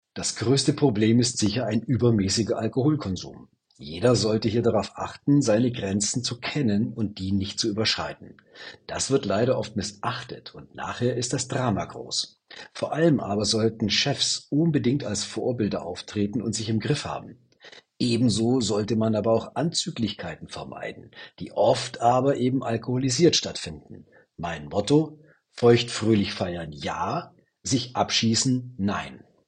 Er ist ehemaliger Leistungssportler, Mental- und Kommunikationscoach.